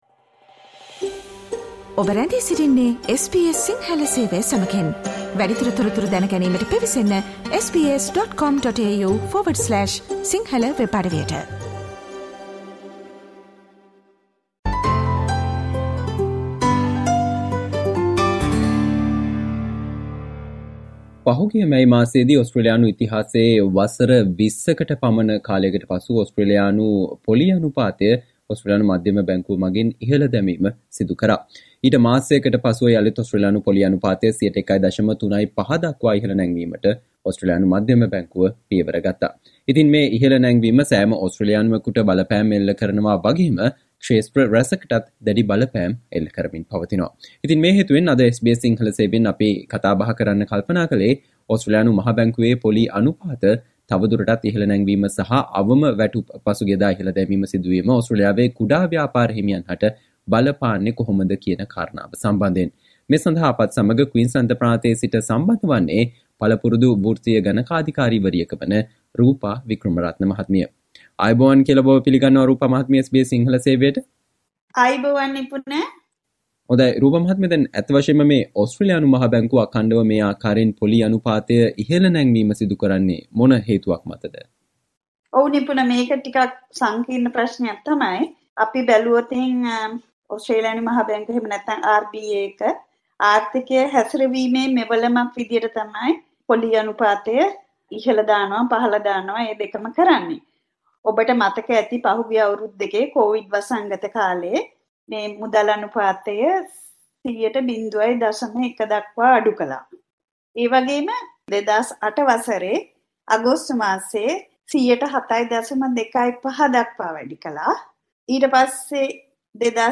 ඕස්ට්‍රේලියානු මහා බැංකුවේ පොලී අනුපාත තවදුරටත් ඉහළ නැංවීම සහ අවම වැටුප් ඉහලදැමීම ඕස්ට්‍රේලියාවේ කුඩා සහ මධ්‍යම පරිමාණ ව්‍යාපාරිකයින් හට බලපාන අයුරු සම්බන්ධයෙන් වන සාකච්චාවට සවන්දෙන්න